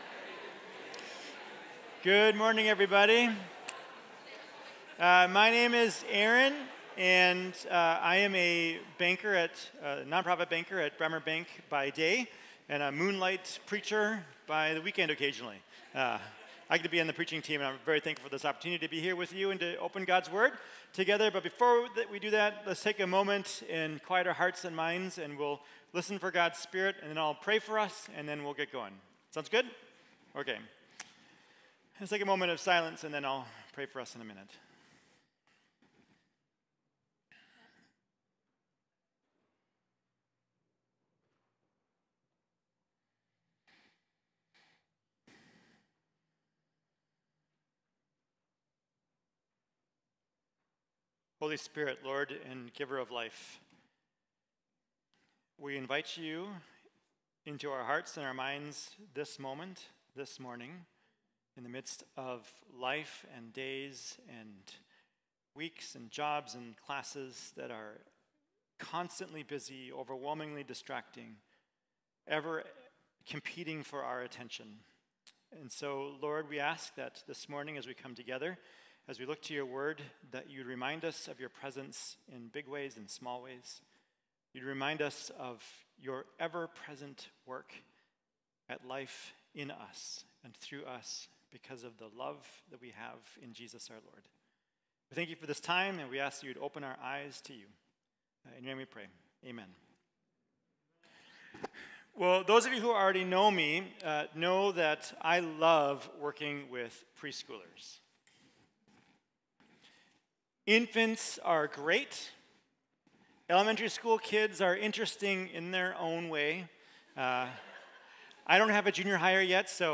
Mill City Church Sermons Relating: Psalms of Wonder May 20 2024 | 00:34:18 1x 00:00 / 00:34:18 Subscribe Share RSS Feed Share Link Embed